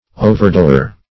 Overdoer \O`ver*do"er\, n. One who overdoes.